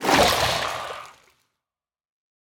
Minecraft Version Minecraft Version 1.21.5 Latest Release | Latest Snapshot 1.21.5 / assets / minecraft / sounds / mob / drowned / convert1.ogg Compare With Compare With Latest Release | Latest Snapshot